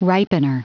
Prononciation du mot ripener en anglais (fichier audio)
Prononciation du mot : ripener